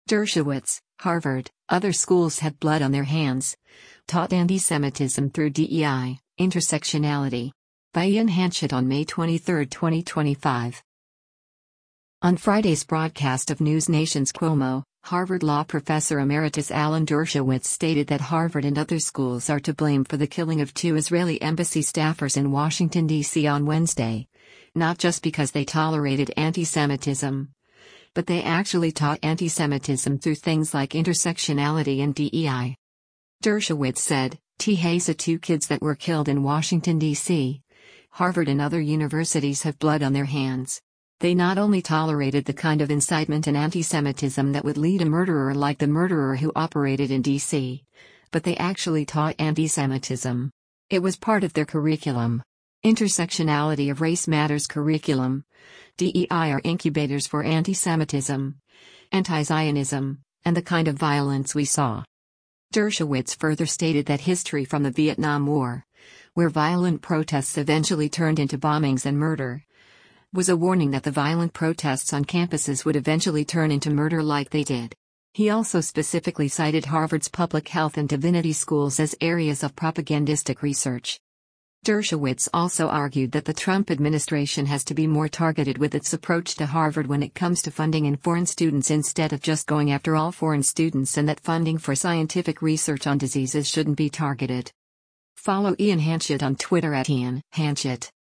On Friday’s broadcast of NewsNation’s “Cuomo,” Harvard Law Professor Emeritus Alan Dershowitz stated that Harvard and other schools are to blame for the killing of two Israeli Embassy staffers in Washington, D.C. on Wednesday, not just because they tolerated antisemitism, “but they actually taught antisemitism” through things like intersectionality and DEI.